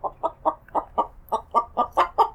sounds_chicken_02.ogg